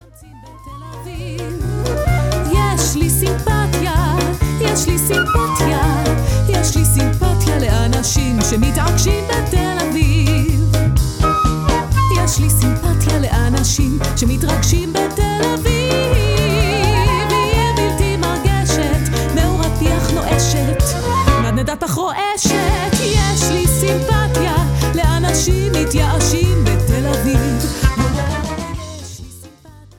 Sensuous female vocals